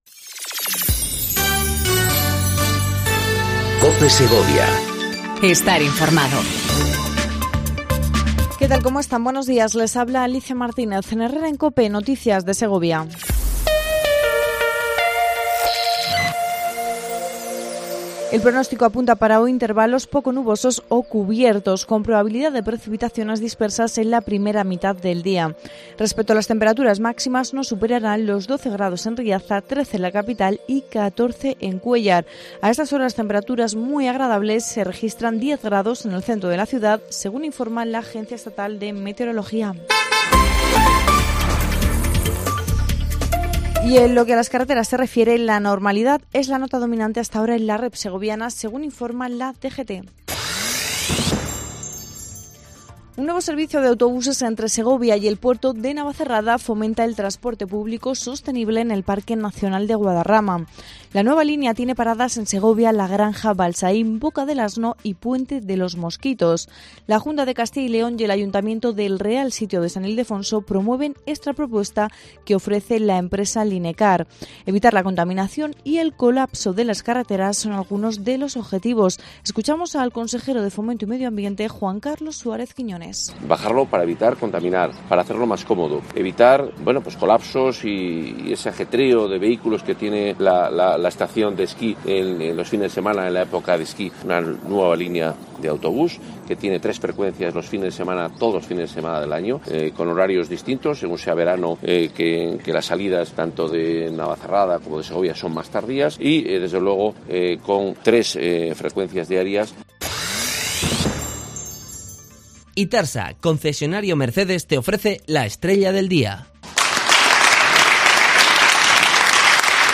INFORMATIVO LOCAL 08:25 COPE SEGOVIA 04/03/19
AUDIO: Segundo informativo local en cope segovia